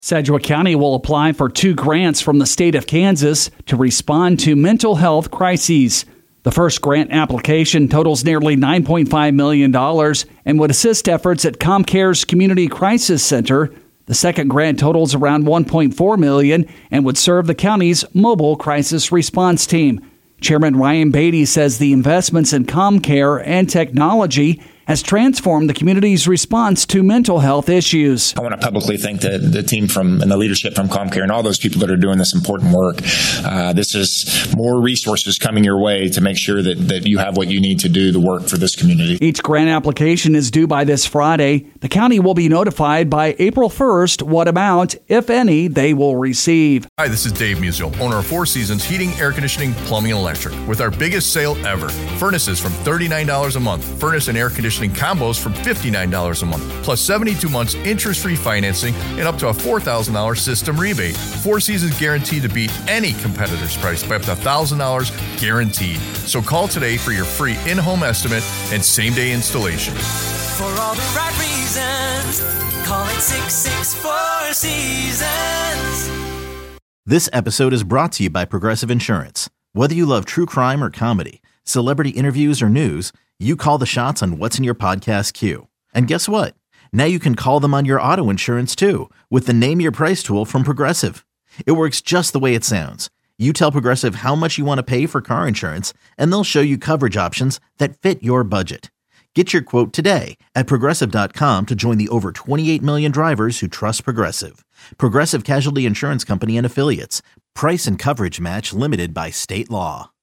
KNSS: On Demand - KNSS News story - Sedgwick County aims to improve response to mental health episodes